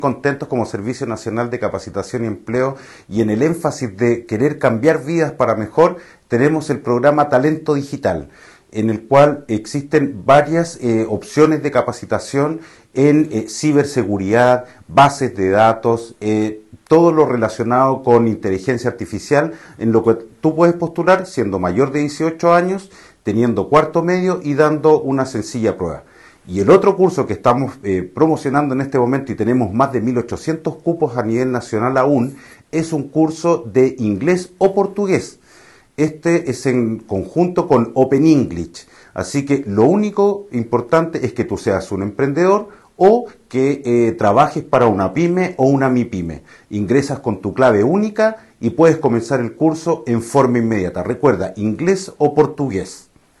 Director regional de Sence Aysén